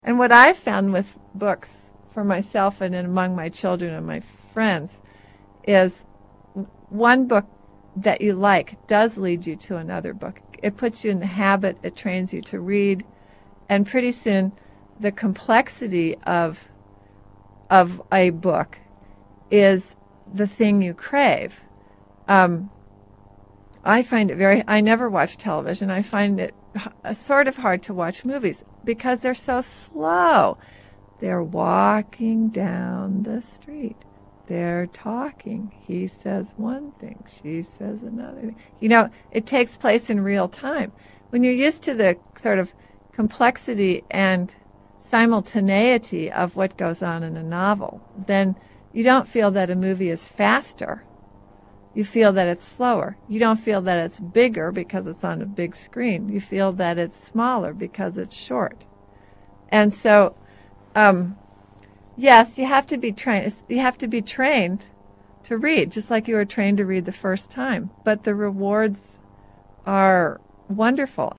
Last week’s Bookworm on radiostation KCRW had Jane Smiley discussing the merits of the novel.